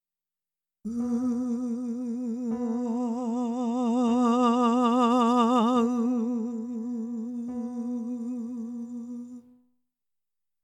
音量注意！
正式なメッサ・ディ・ボーチェのように明確な声量差を作ろうとしなくてもいいので、ほんのちょっぴり質感がしっかりはっきりしたかな？くらいを狙ってみてください。